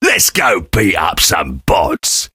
sam_start_vo_02.ogg